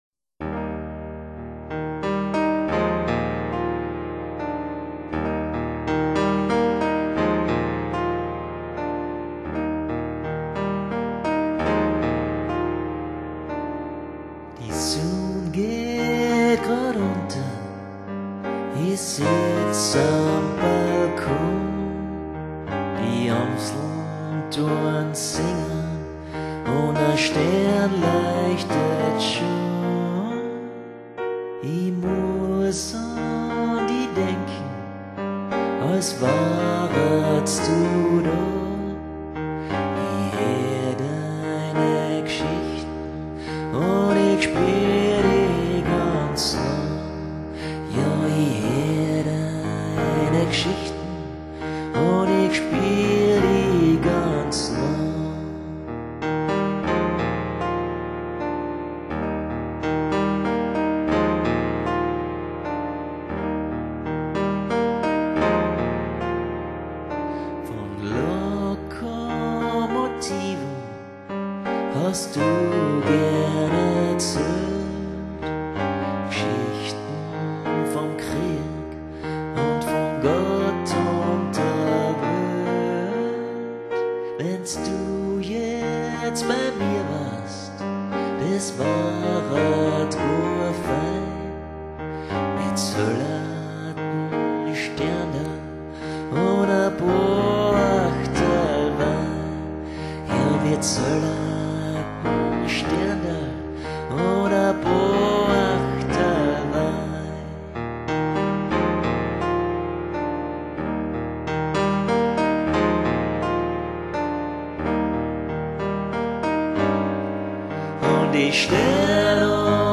vocals, piano